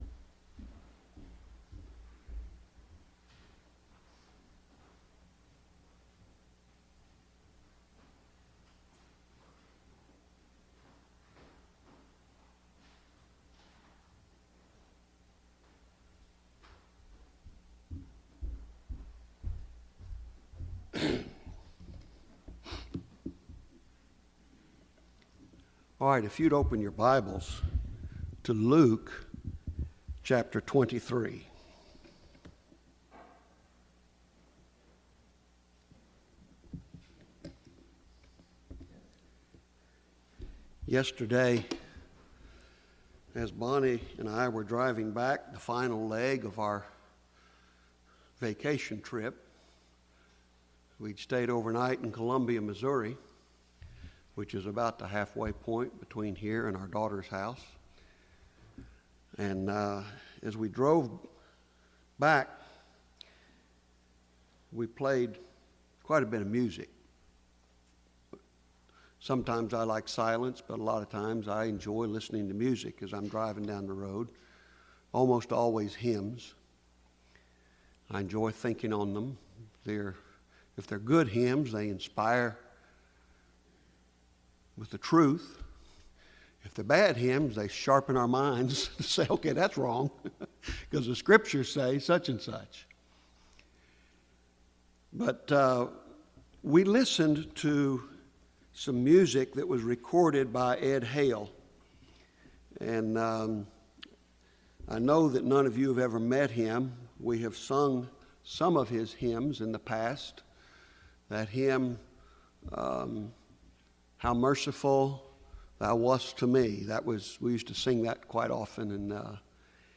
2020-10-04 - Weep Not for Me | SermonAudio Broadcaster is Live View the Live Stream Share this sermon Disabled by adblocker Copy URL Copied!